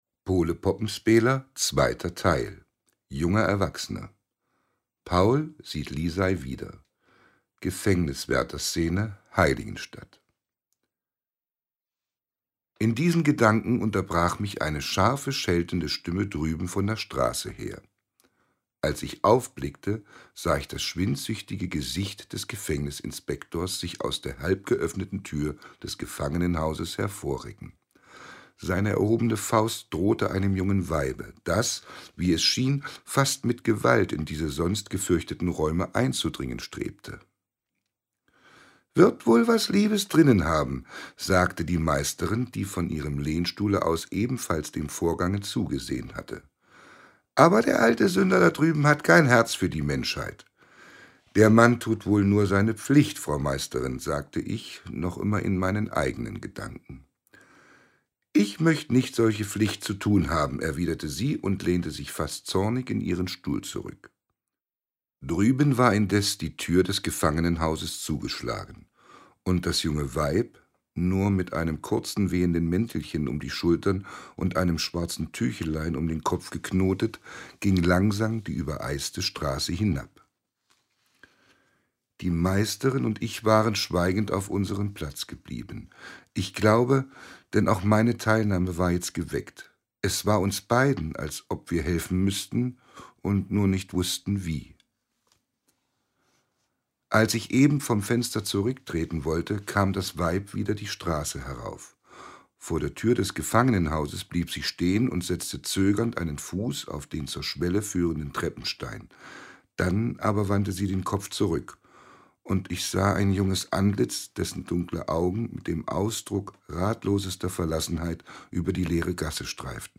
Audioguide zur Ausstellung